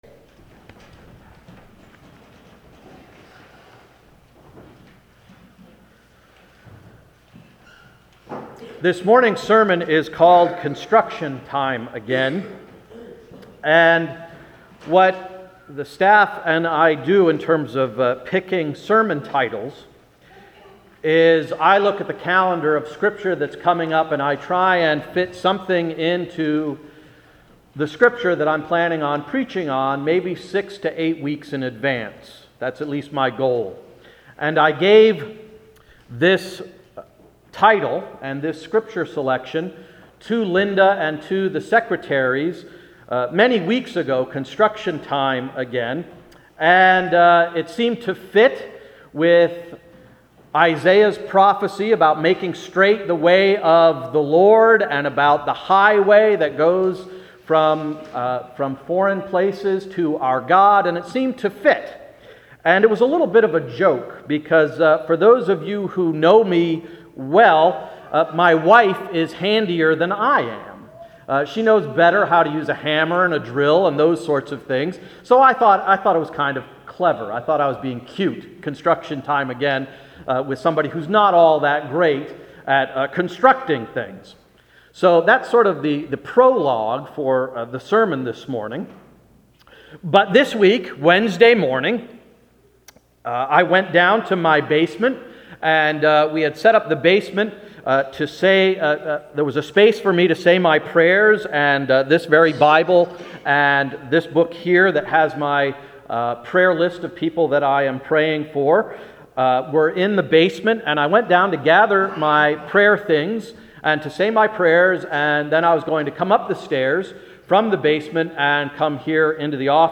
Sermon of December 5, 2010–“Construction Time Again”